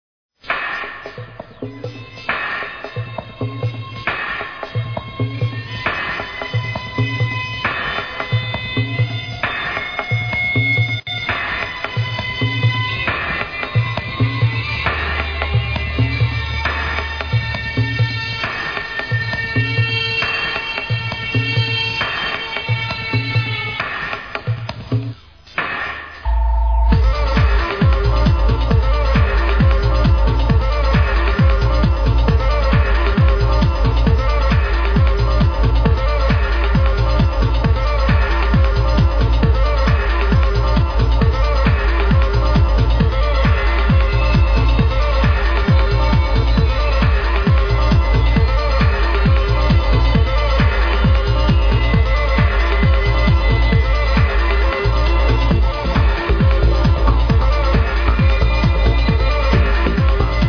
Trippy prog track